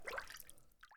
water-bathe-1
bath bathroom bathtub bubble burp click drain drip sound effect free sound royalty free Nature